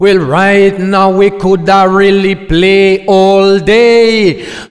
OLDRAGGA3 -R.wav